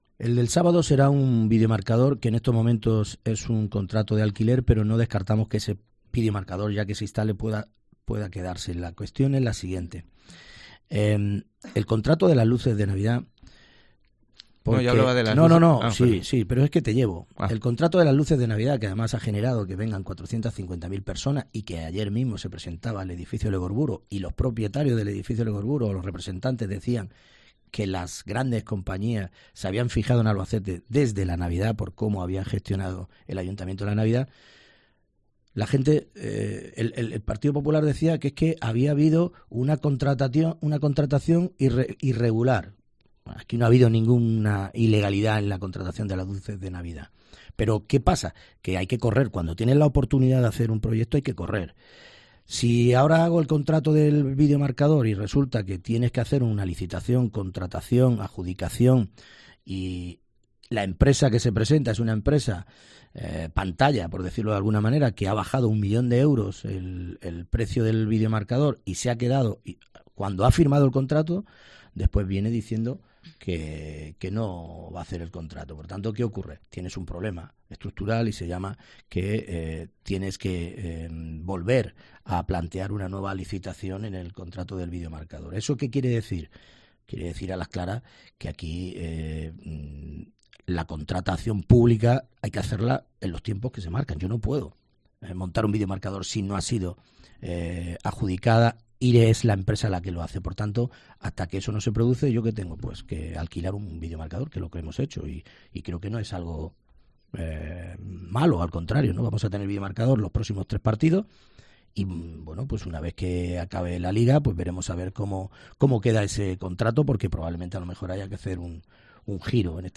El alcalde Emilio Sáez asegura en COPE que la empresa adjudicataria no ha cumplido y es posible que haya que sacar de nuevo el concurso y hacerlo por lotes para ser ágiles